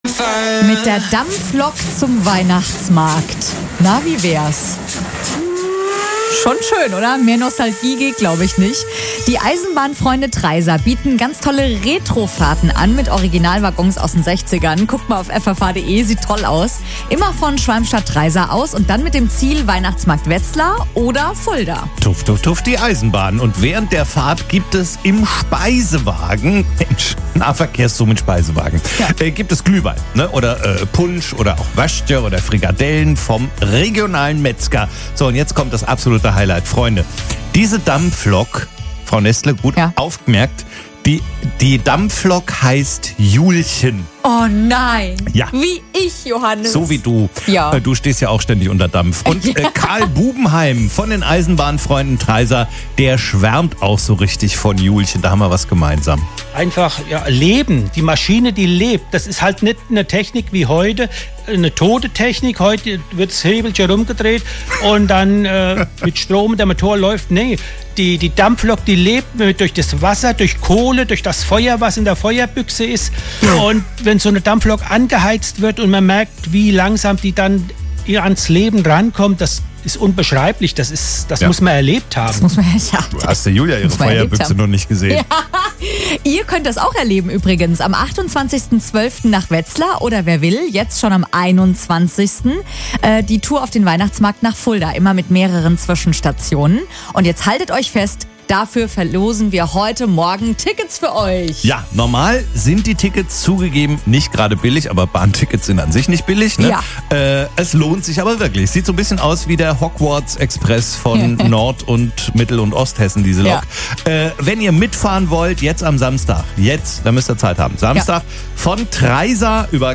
Interviews, Sendungen und Berichte
Unser Schatzmeister im Interview mit FFH